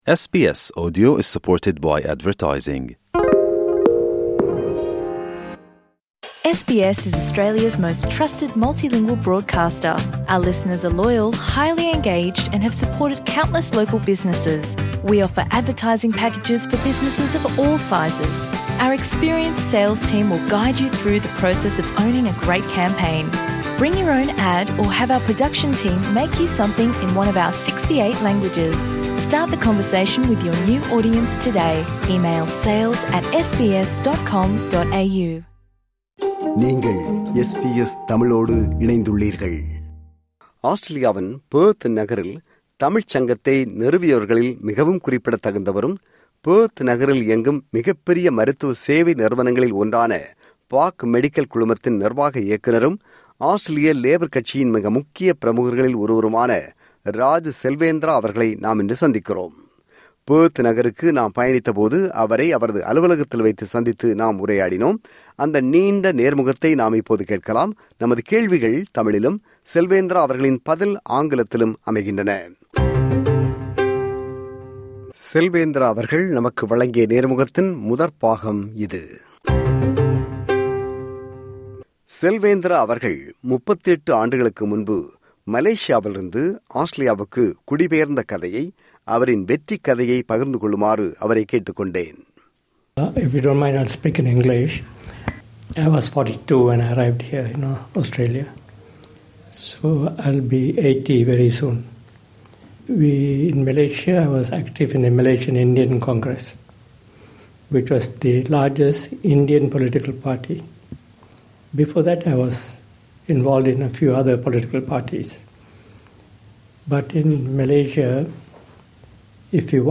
Interview - Part 1